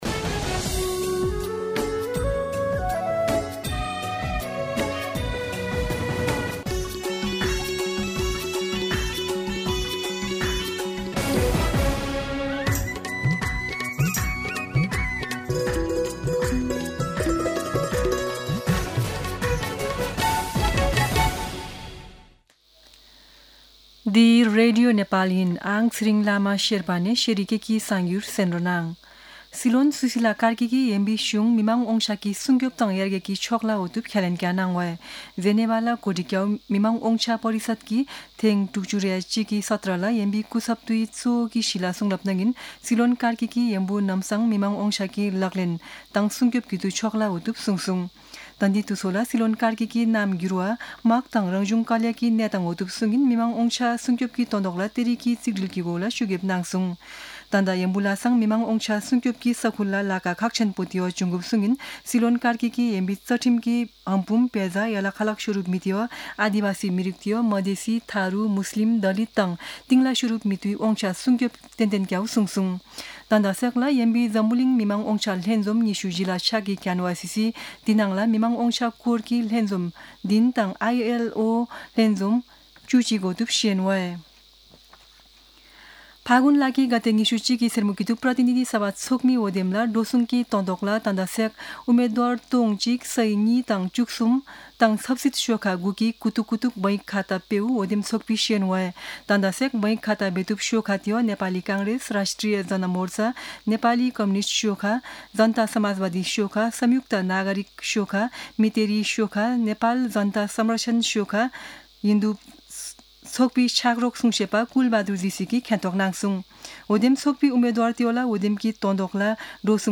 शेर्पा भाषाको समाचार : १२ फागुन , २०८२
Sherpa-News-12.mp3